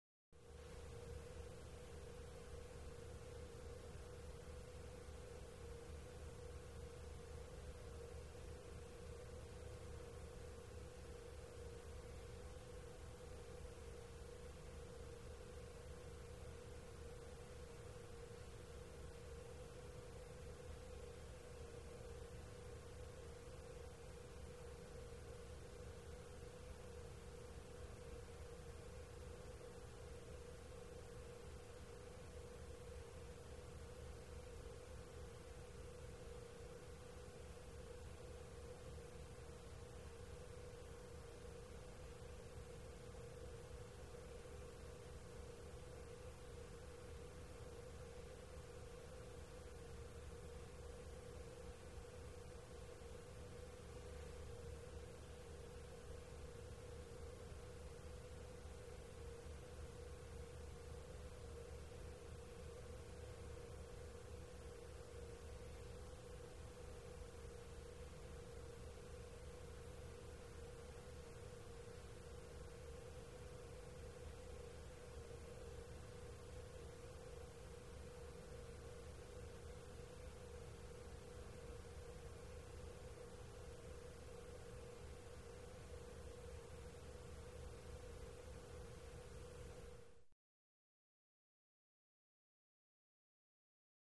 Gym Bathroom | Sneak On The Lot